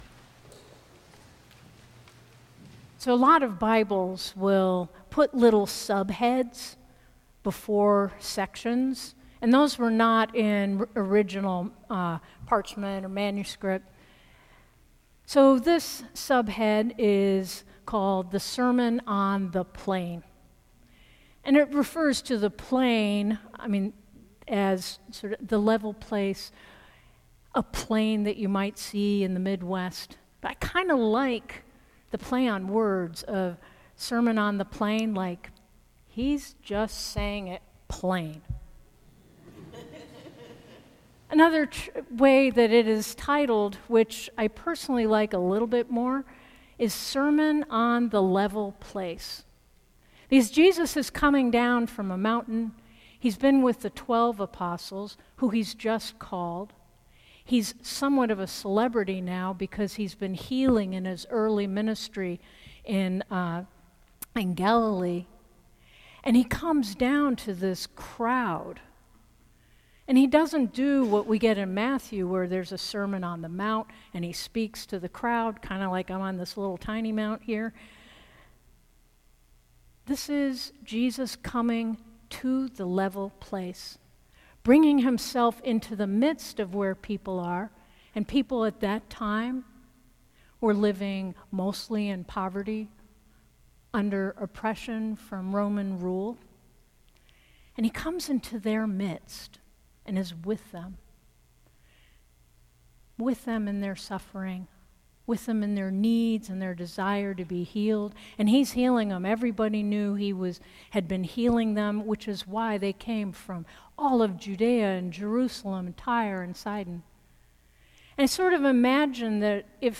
St. Augustine by-the-Sea Sermons